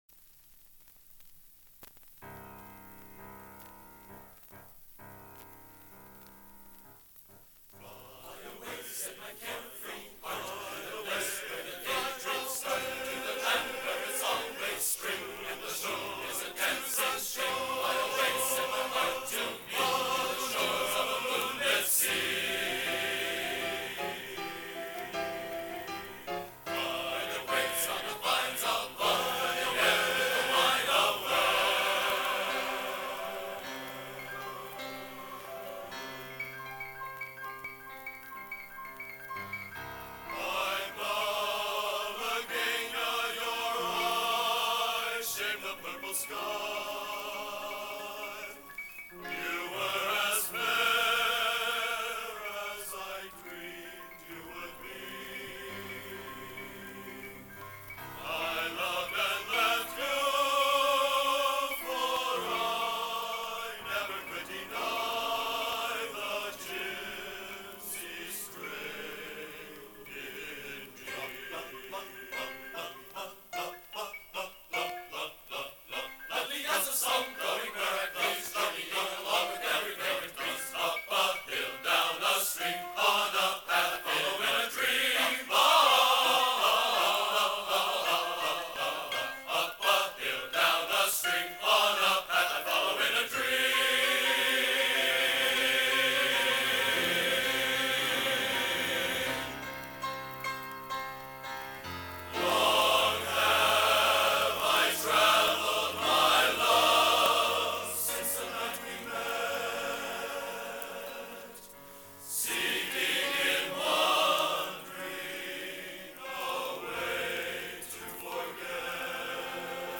Location: West Lafayette, Indiana
Genre: | Type: Studio Recording